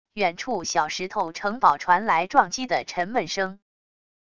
远处小石头城堡传来撞击的沉闷声wav音频